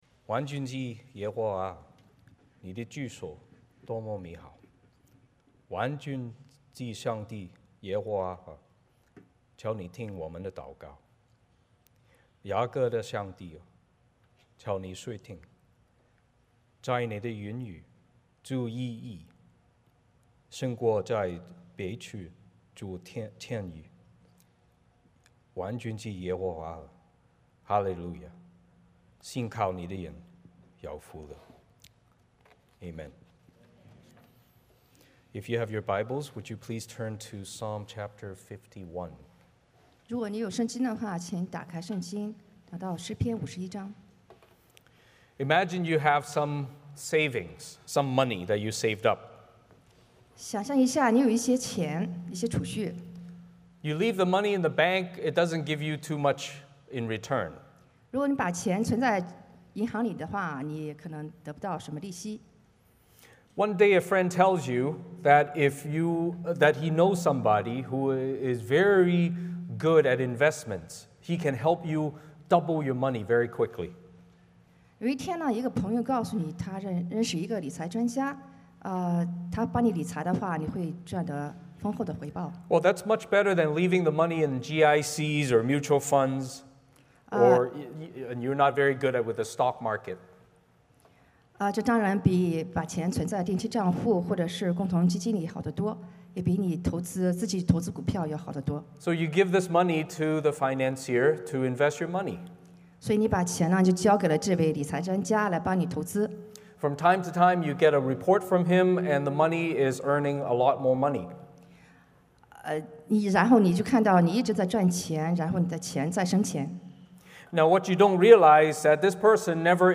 Passage: 诗篇 51:1-19 (中文标准译本) Service Type: 主日崇拜 欢迎大家加入我们的敬拜。